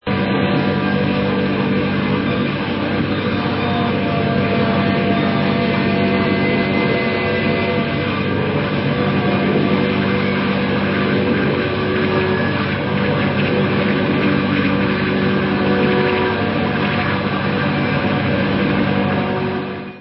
-ULTRA RARE MIND EXPANDING FUZZ FILLED GEMS! -